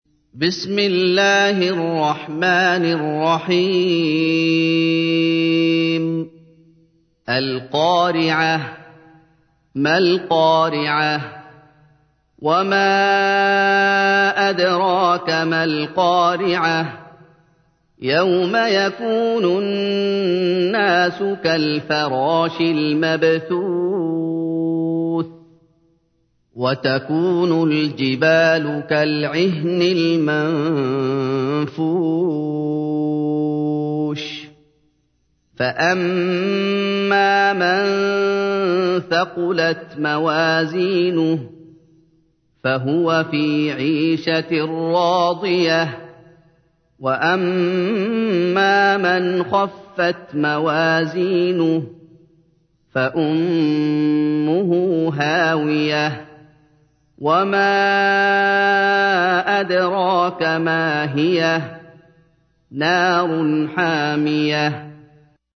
تحميل : 101. سورة القارعة / القارئ محمد أيوب / القرآن الكريم / موقع يا حسين